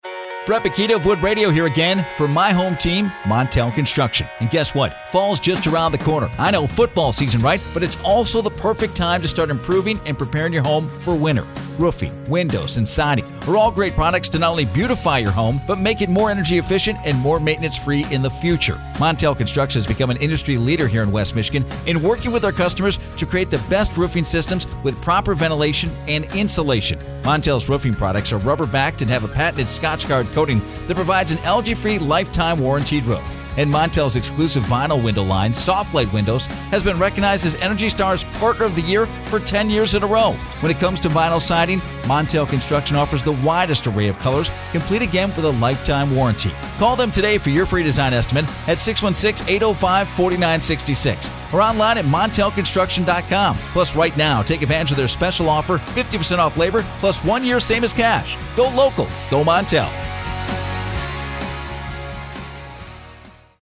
catchy jingle